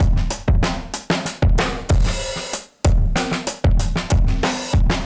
Index of /musicradar/dusty-funk-samples/Beats/95bpm/Alt Sound
DF_BeatB[dustier]_95-04.wav